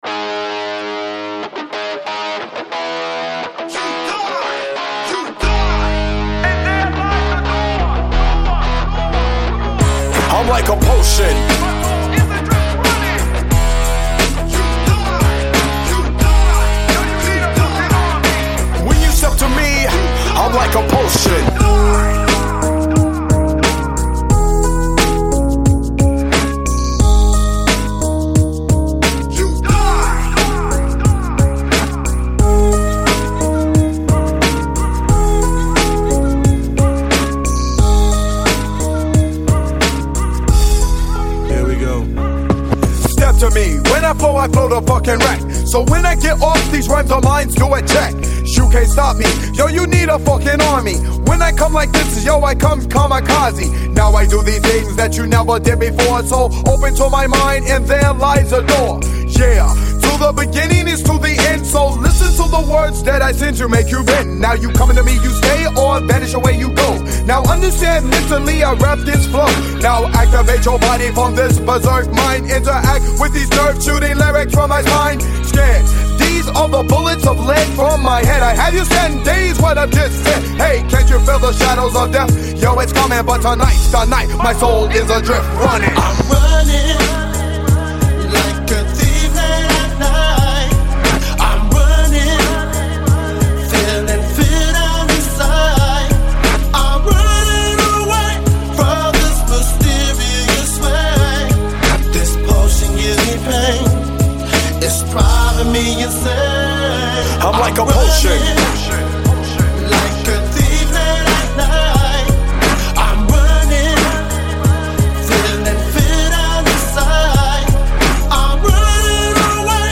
Жанр: Hip-Hop
Рэп Хип-хоп.